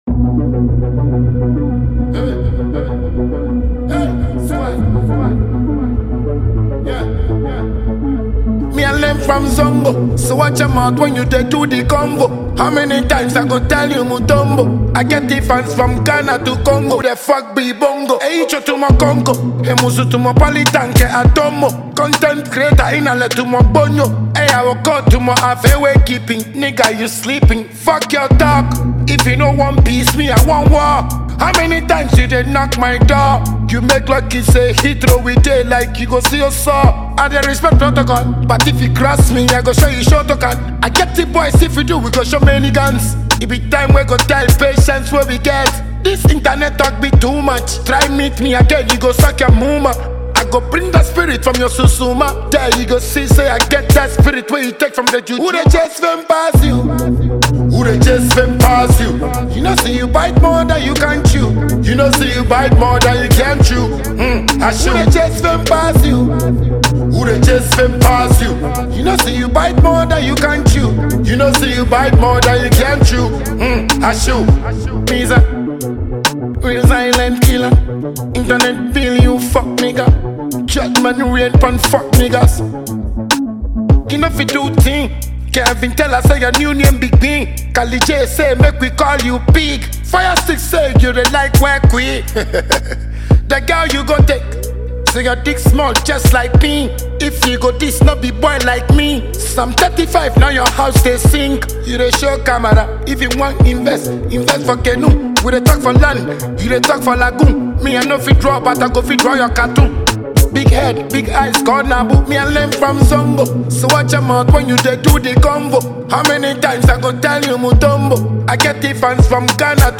Celebrated dancehall musician